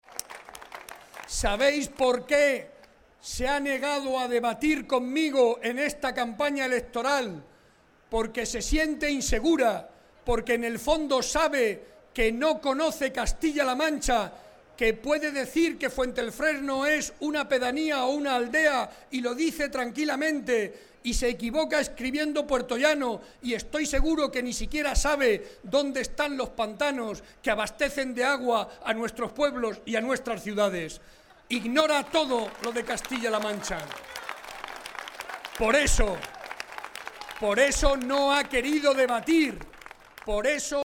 El presidente, que esta tarde estuvo en Fuente el Fresno (Ciudad Real), resaltó el gran esfuerzo del Gobierno regional para mejorar la calidad de vida de los vecinos de Fuente el Fresno y de Castilla-La Mancha.
02-BARREDA-FUENTELEFRESNO2.mp3